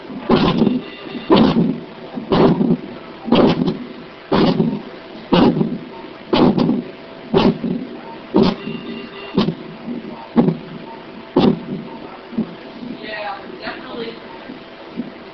6.1. Simulated phrenic nerve injury identified by fetal heart monitor. The initial sound is normal diaphragm contractions during phrenic nerve pacing. As the pacing amplitude decreases, the tone of the contraction changes, indicating phrenic nerve injury.